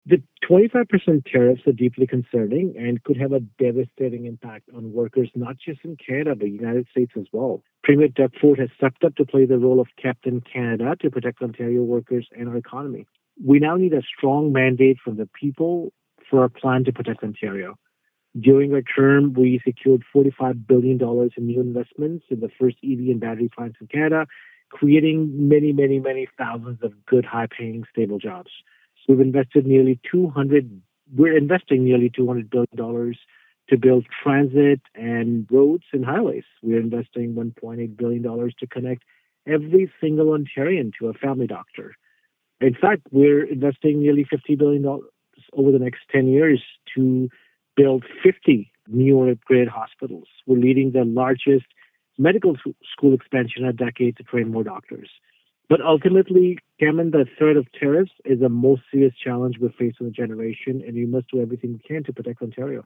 He was available by phone for this interview.
Here’s our interview: